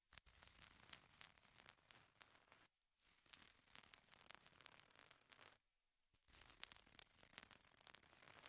OWI " 雨落在裸露的地面上
描述：使用打火机创建。
Tag: 性质 OWI 愚蠢 安静